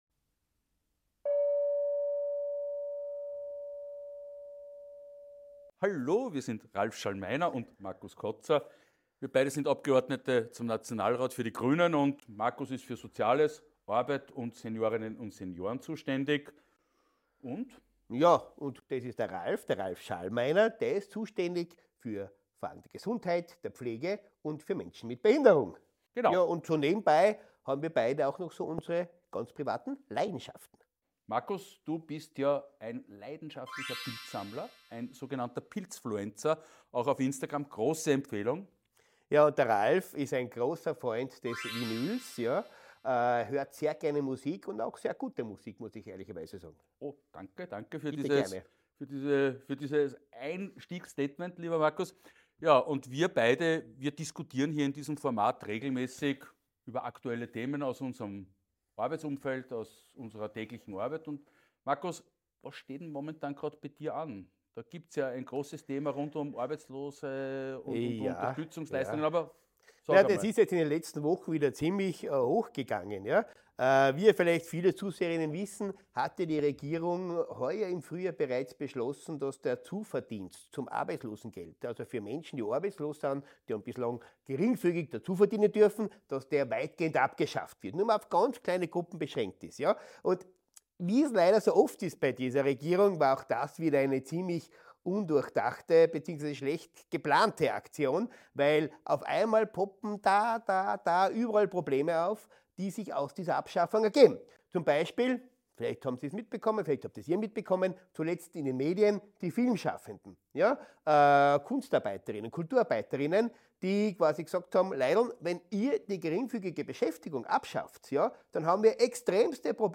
Und wieso ist das 2025 noch geplante Einbehalten des Pflegebonus durch die Salzburger Landesregierung eine Frage von Wertschätzung? Das und noch einiges mehr versuchen Markus Koza und Ralph Schallmeiner in einem launigen Dialog miteinander zu erklären.